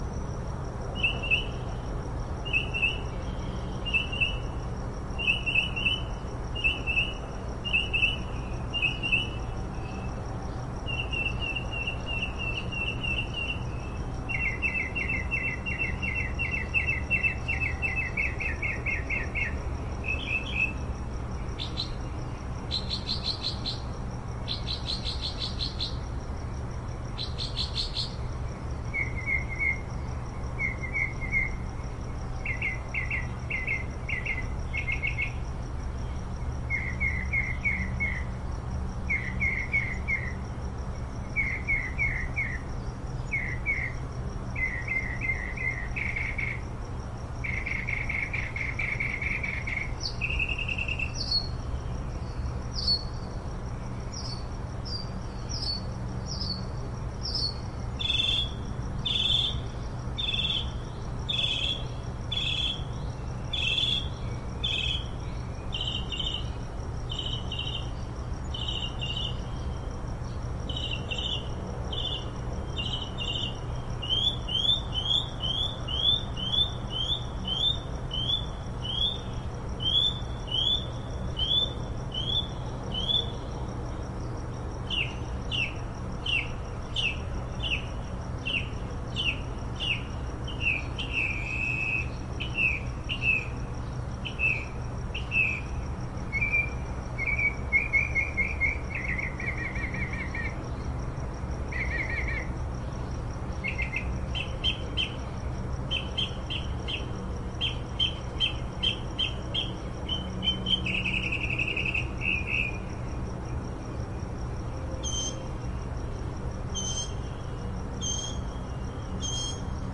雨声 " 城市中的稳定的雨
描述：在我的窗台上用Q3HD录制。更多的是暴雨，背景有“城市”噪音。
标签： 声音 晚上 加利福尼亚州 稳定 汽车 雨量充沛 城市
声道立体声